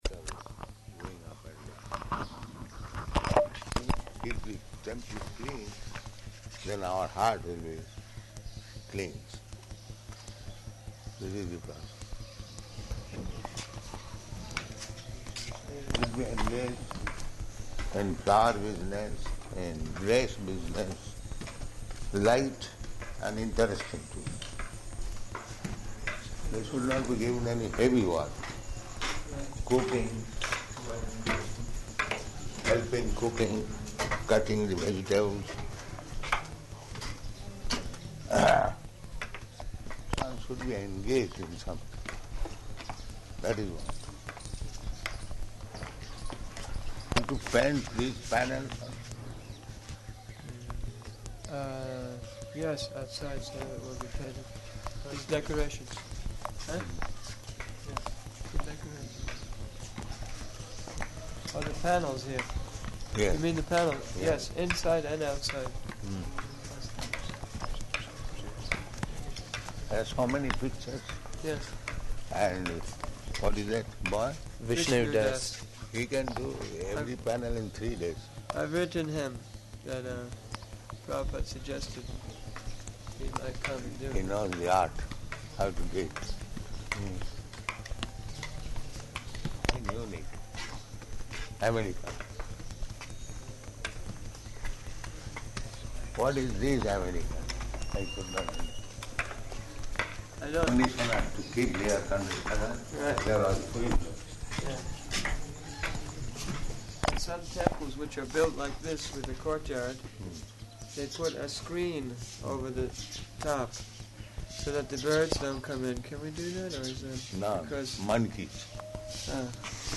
Morning Walk [partially recorded]
Type: Walk
Location: Vṛndāvana